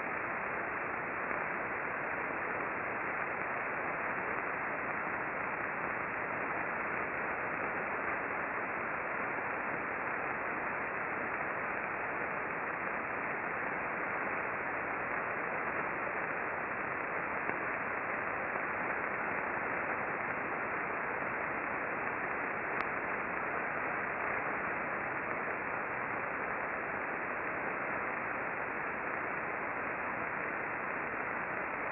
The bursts on the lower channel mostly were L-bursts but some S-bursts also occurred. The bursts on the higher channel were L-bursts.